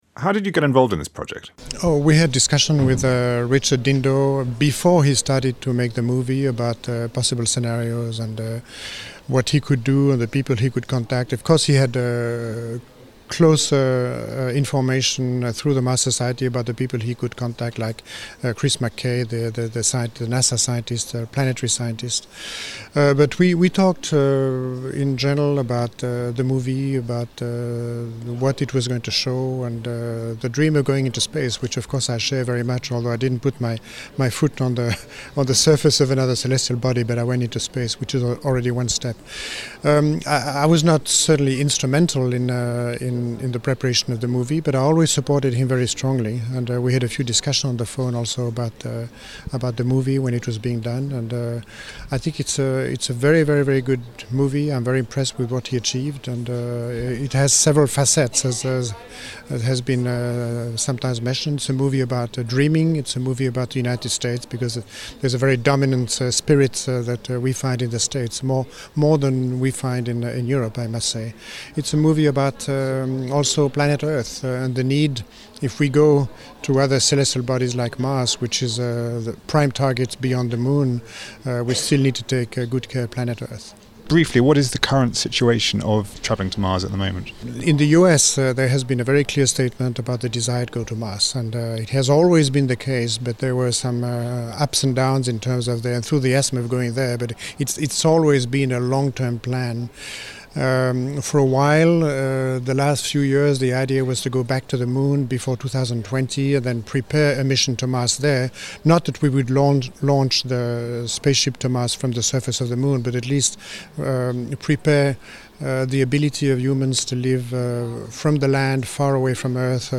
Claude Nicollier interview
Astronaut Claude Nicollier talks about missions to Mars.